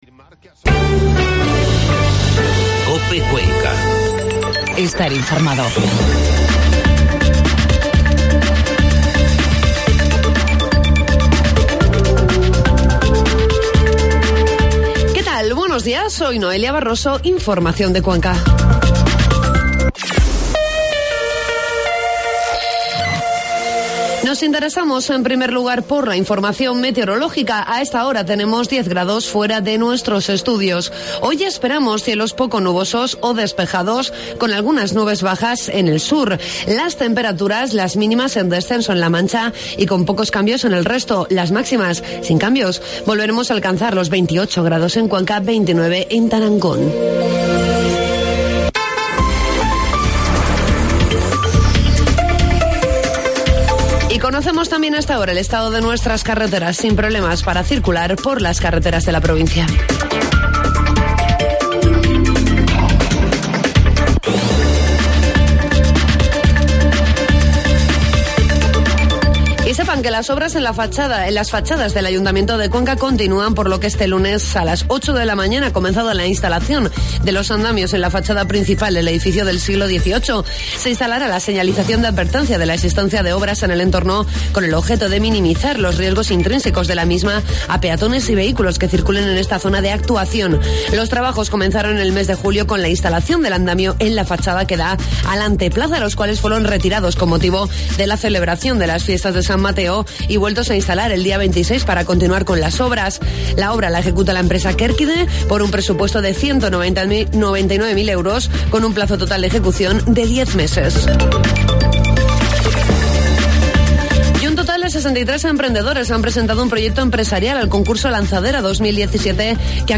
Informativo matinal COPE Cuenca 9 de octubre